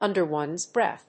アクセントùnder one's bréath